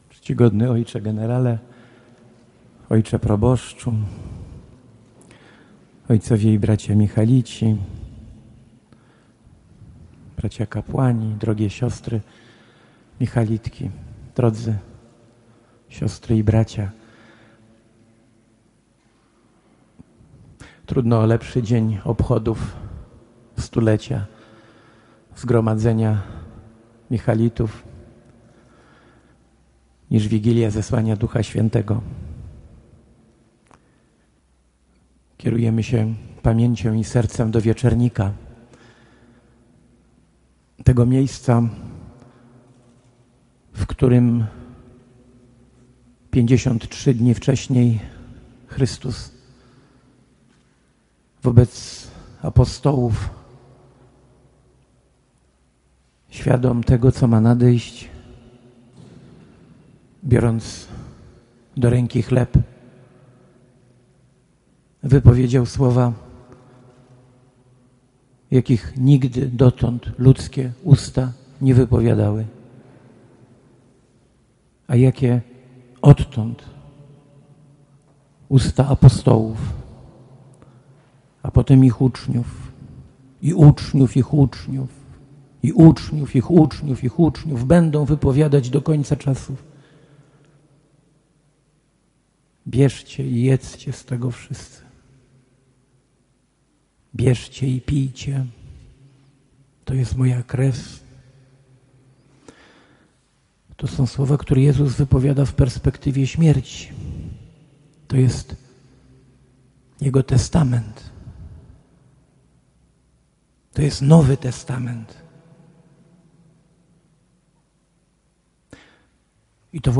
KONFERENCJA NAUKOWA NA 100-LECIE ZATWIERDZENIA ZGROMADZENIA – Michalici Toruń
Uczestnicy wysłuchali sześciu referatów w dwóch sesjach sympozjalnych.